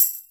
drum-hitwhistle.wav